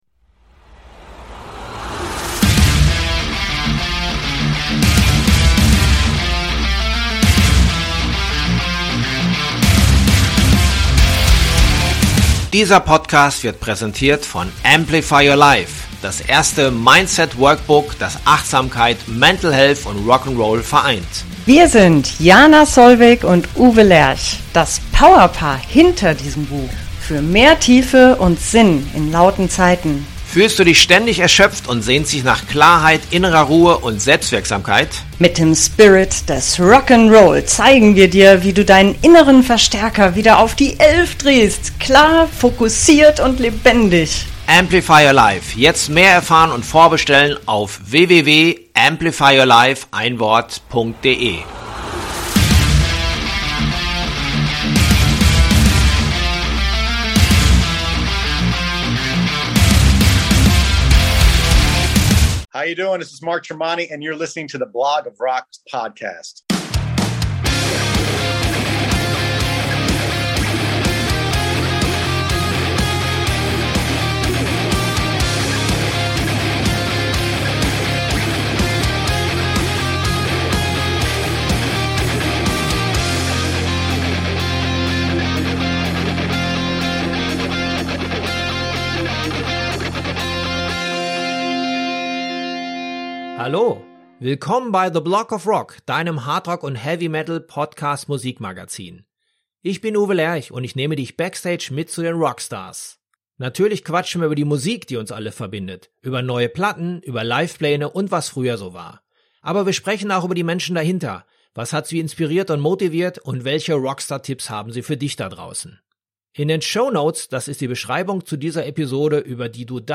Während beide Bands gerade Pause haben, ist er mit seinen Solo-Act TREMONTI auf der Spur und hat bereits vor zwei Monaten mit „Marching In Time“ ein fettes Brett abgeliefert. Ich erwischte ihn on the road im Hotelzimmer.